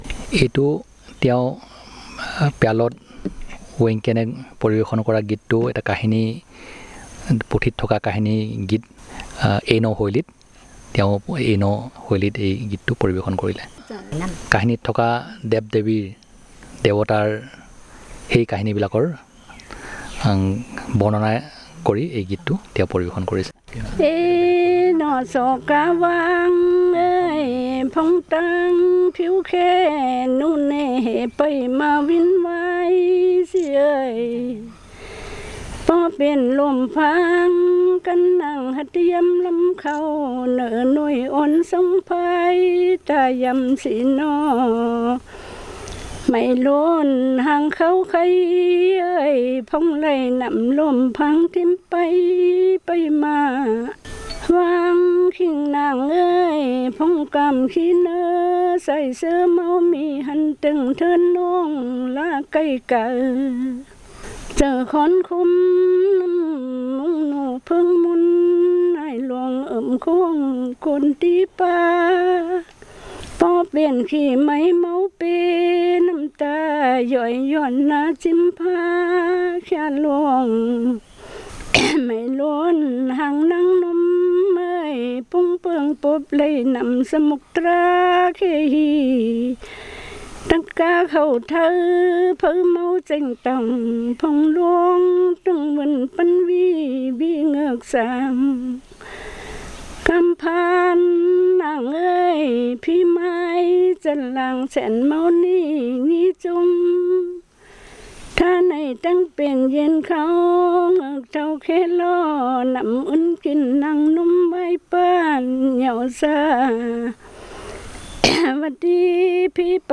Performance of a story song about gods and goddesses